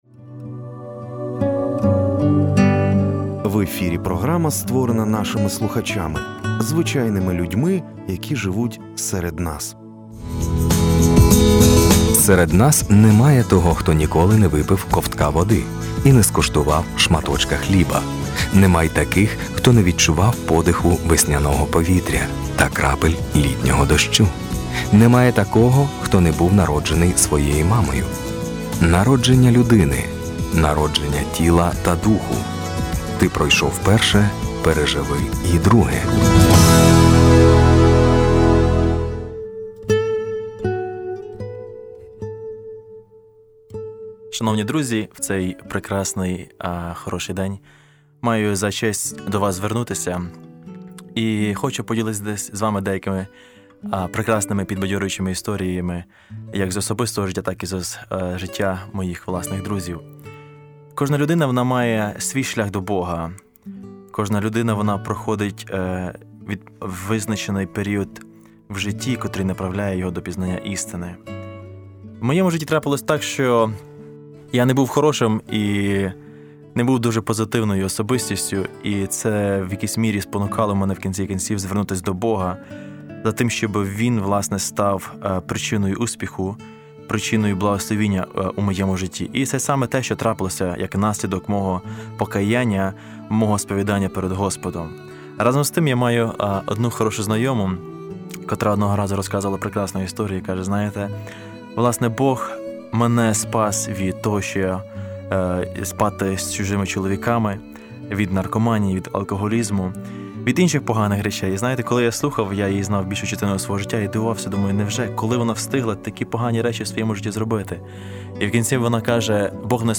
З перших вуст: розповідь хлопця про неймовірну трансформацію його знайомої. Перебуваючи у полоні блуду та наркотиків, вона зустріла Бога, Який подарував їй нове життя, сповнене надії та віри.